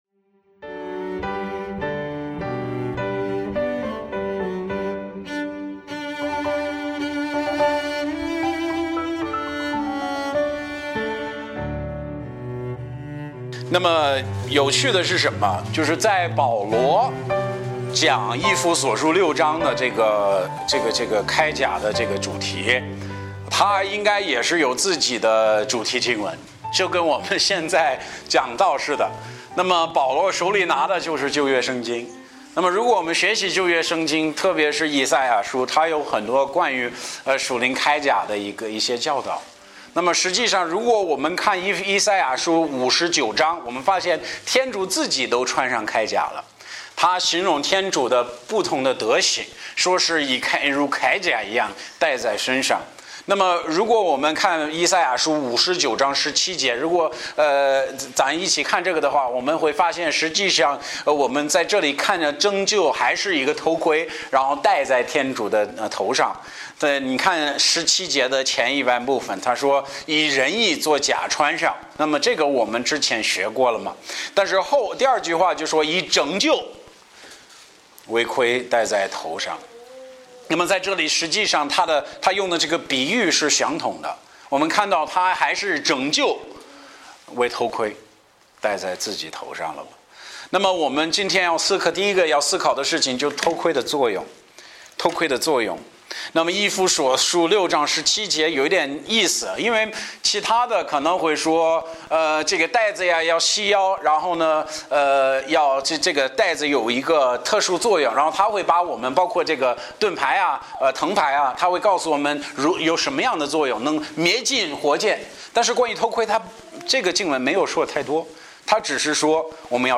Bible Text: 以弗所书6:17 | 讲道者